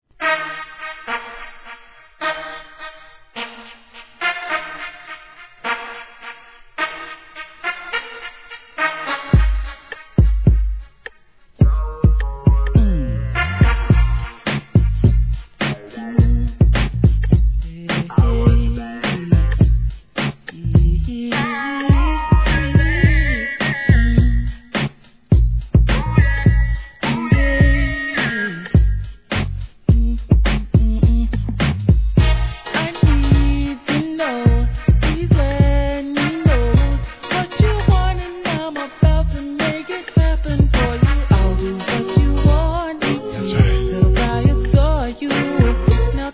1. HIP HOP/R&B
イントロからヴォコーダーを駆使したWEST SDIEフレイバーたっぷりの'98年作品!!